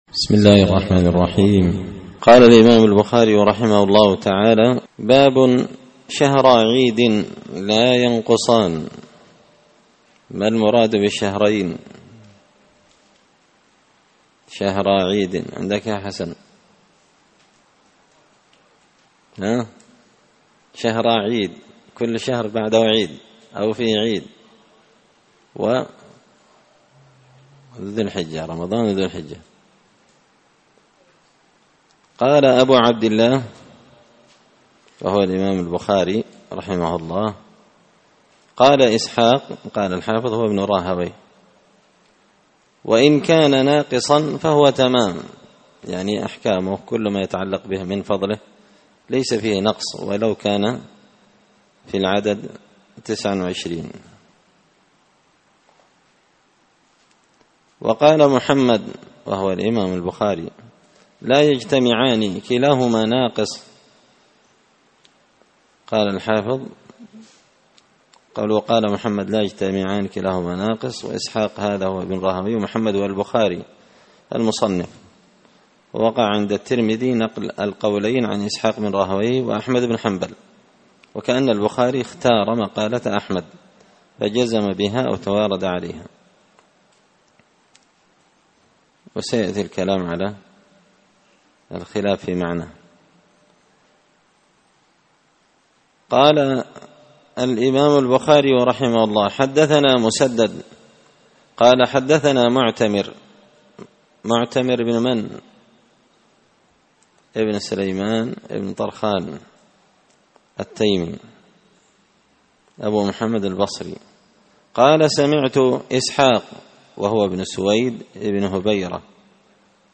كتاب الصيام من صحيح البخاري الدرس الحادي عشر (11) باب شهرا عيد لاينقصان